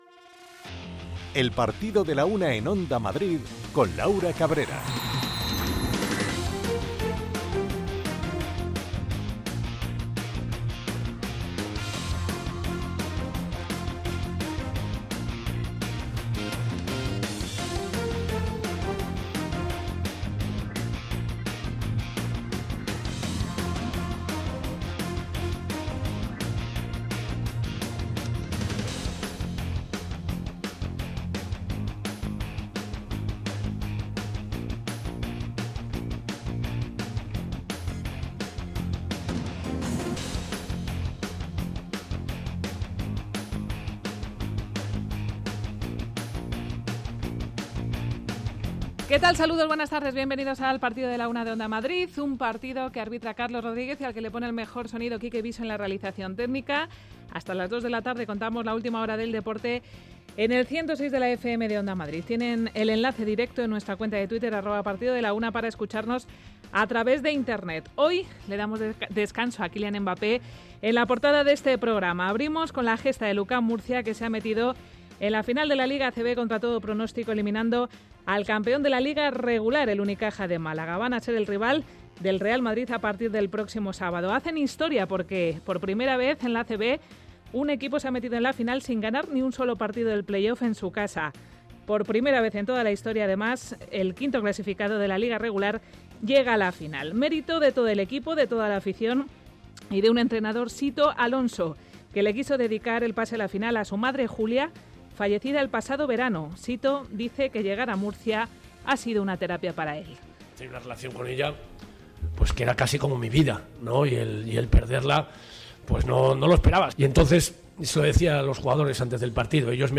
Es la referencia diaria de la actualidad deportiva local, regional, nacional e internacional. El rigor en la información y el análisis medido de los contenidos, con entrevistas, reportajes, conexiones en directo y el repaso a la agenda polideportiva de cada día, son la esencia de este programa.